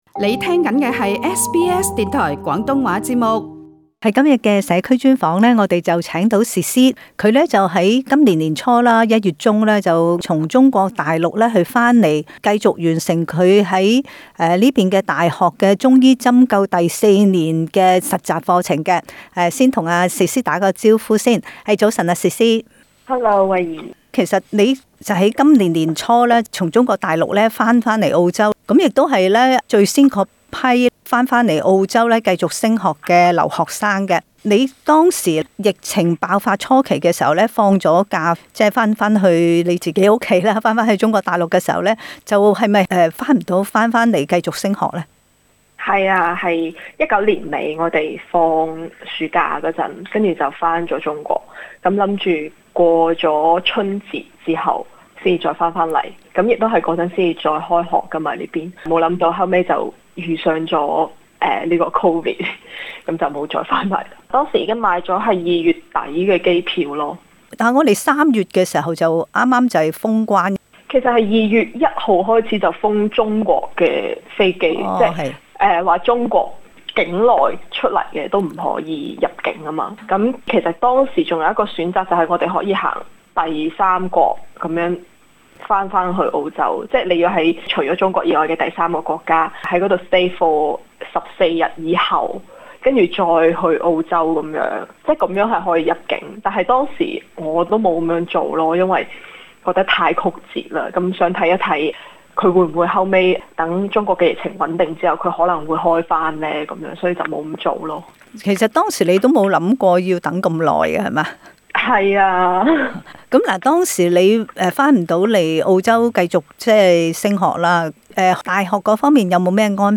【社區專訪】雪梨義工組織SouperStar : 愛心湯送暖行動